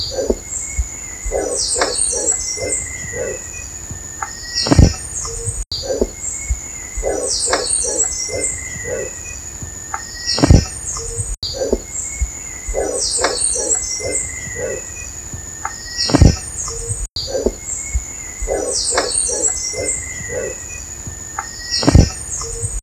Cathartes aura ruficollis - Cuervo de cabeza roja
Carecen de siringe, por lo cual no emiten vocalizaciones.